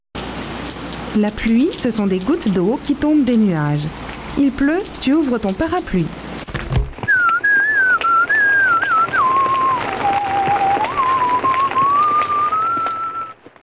/ DictionnaireNathanDKMMMFD.iso / r / rain / rain0m0d.wav ( .mp3 ) < prev next > Waveform Audio File Format | 1995-04-13 | 152KB | 1 channel | 22,050 sample rate | 13 seconds
rain0m0d.mp3